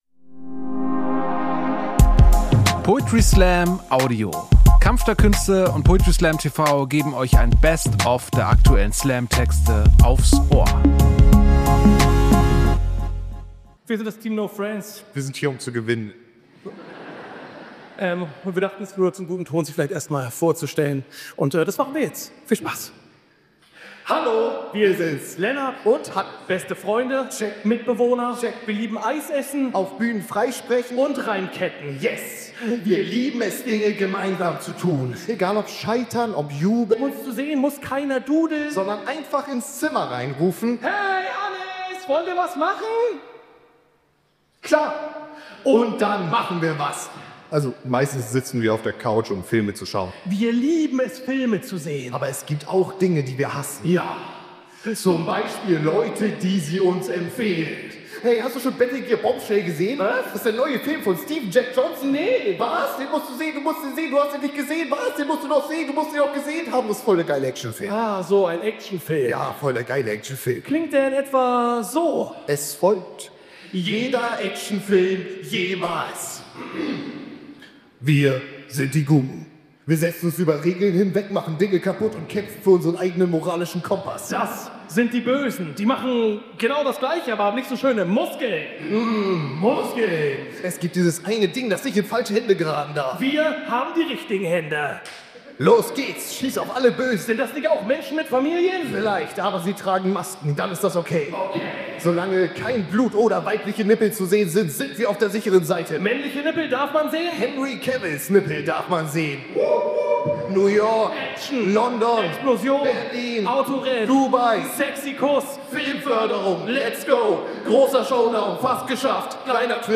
Elbphilharmonie Hamburg Kampf der Künste TV Website: